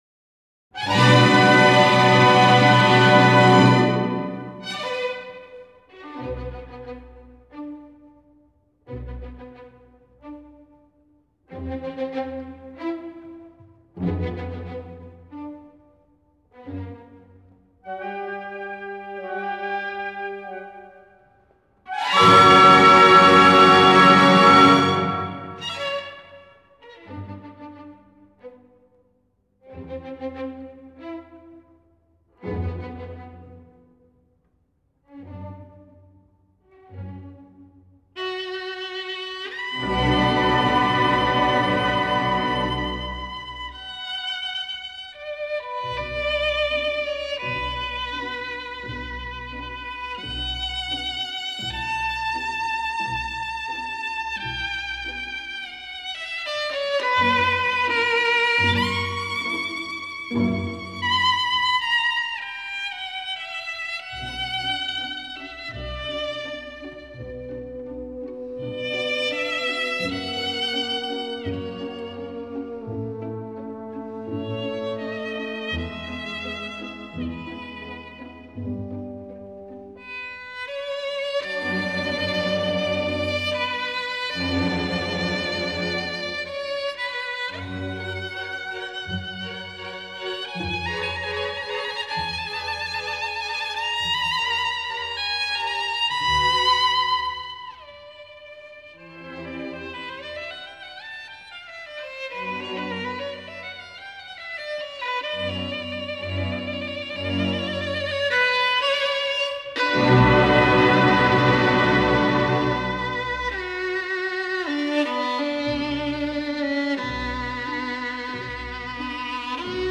帕格尼尼_1号小提琴协奏曲2 -下载地址列表-乐器学习网